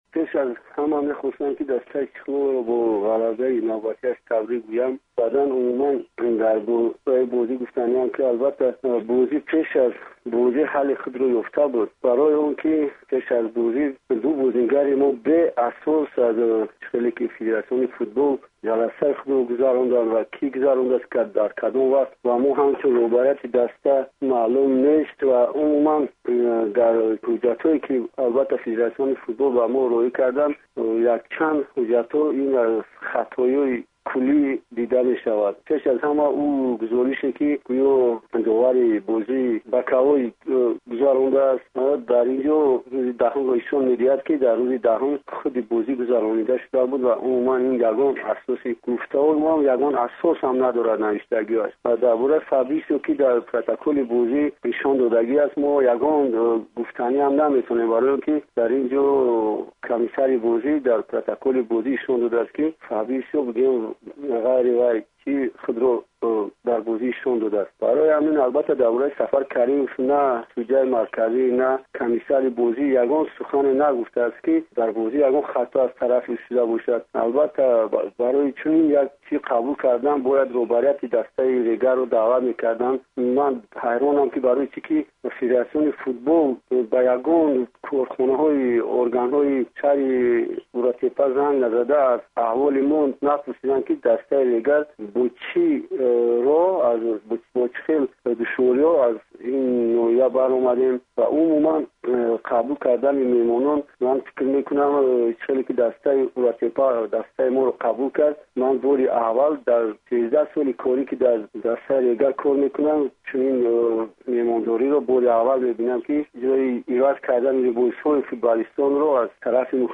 Гуфтугӯ